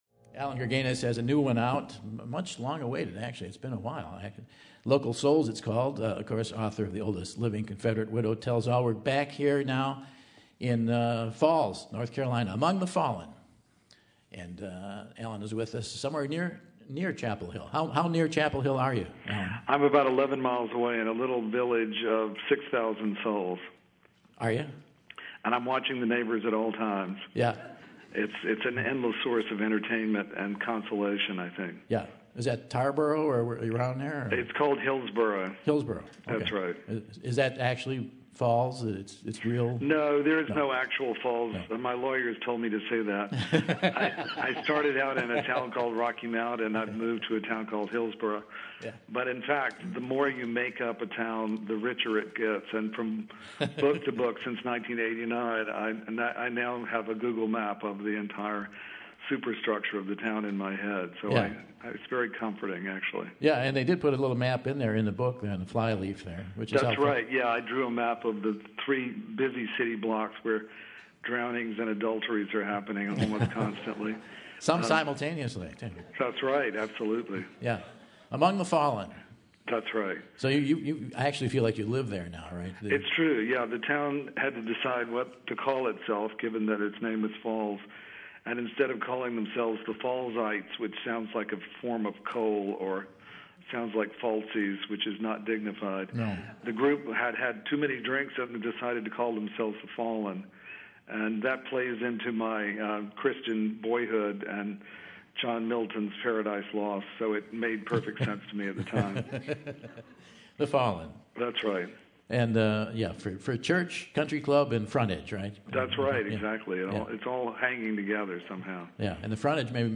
Local Souls and Oldest Living Confedrate Widow Tells All author Allan Gurganus chats with Michael about his books and more!